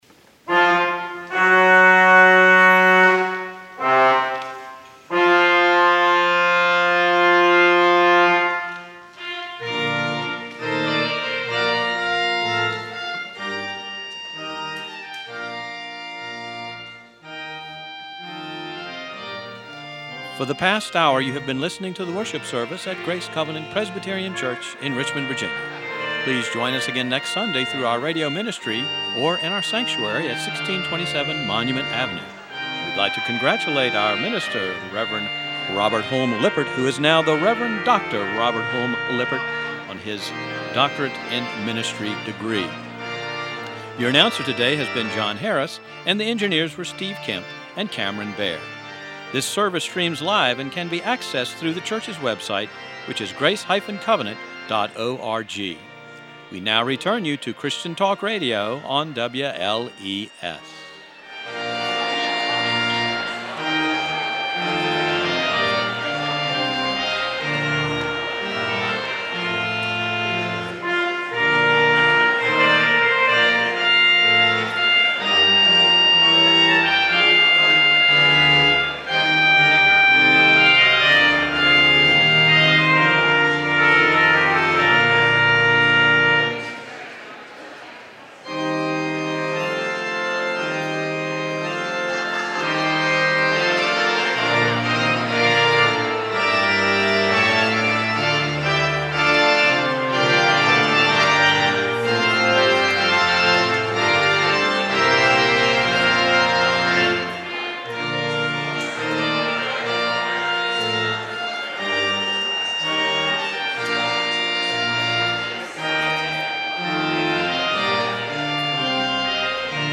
POSTLUDE March in B-Flat Major Norman Coke-Jephcott
organ